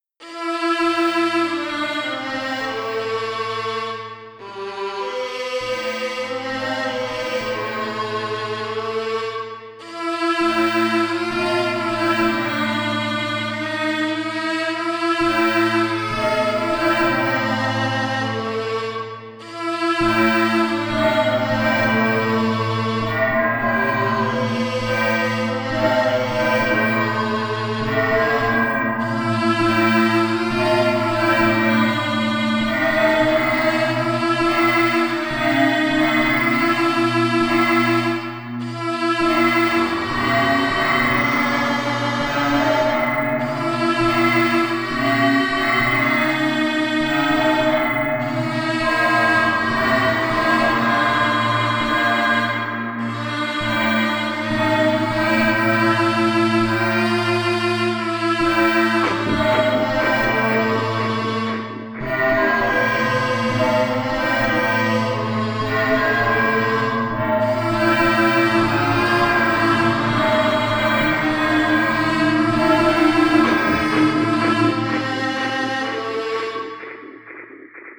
One-hour challenge composition
(17edo)